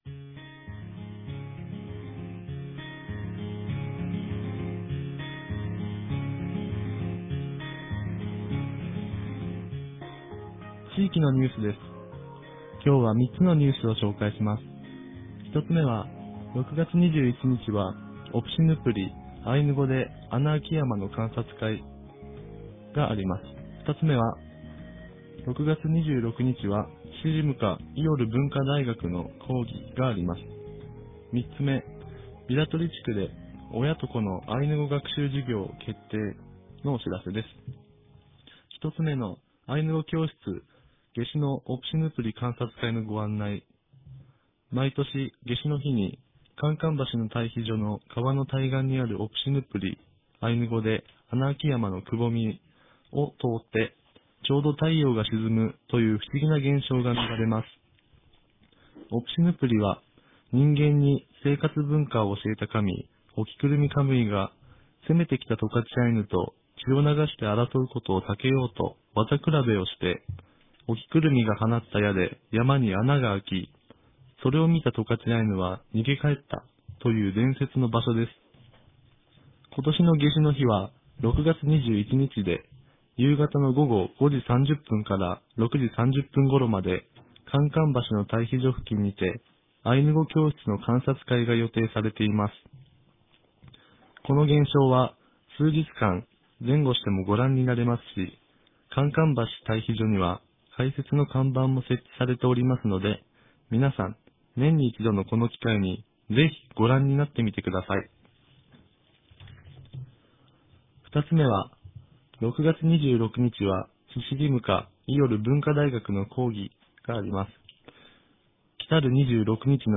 ■地域のニュース